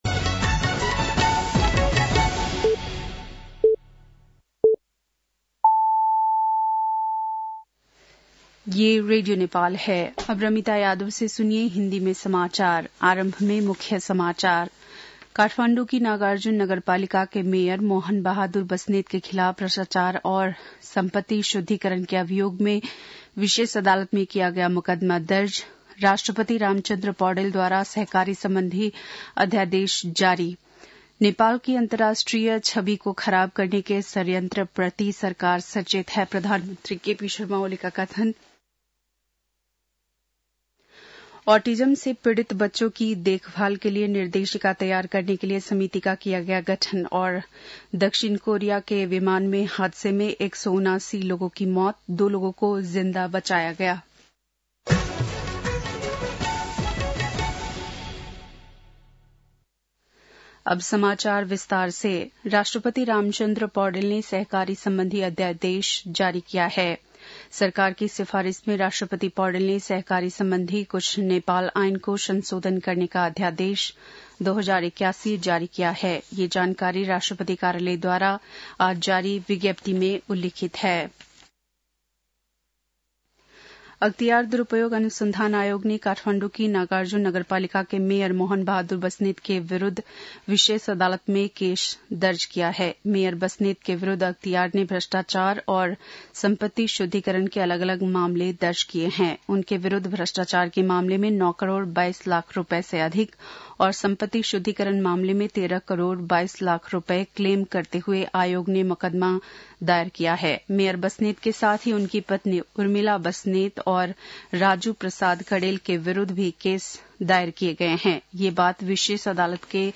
बेलुकी १० बजेको हिन्दी समाचार : १६ पुष , २०८१
10-pm-hindi-news-9-14.mp3